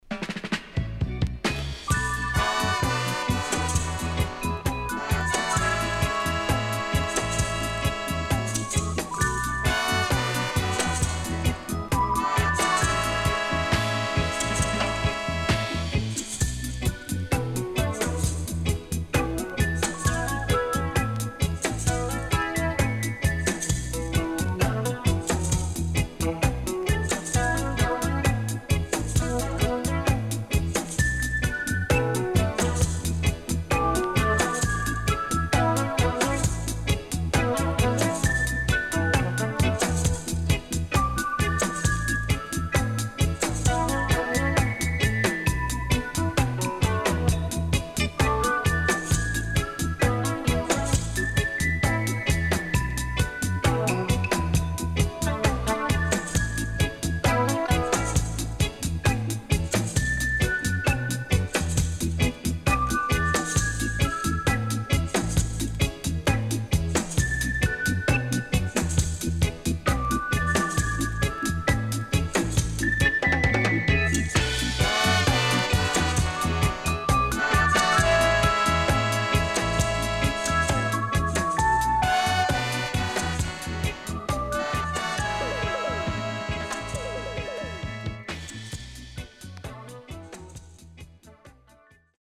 【12inch】
Nice Duet Lovers & Inst
SIDE A:うすいこまかい傷ありますがノイズあまり目立ちません。